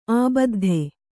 ♪ ābaddhe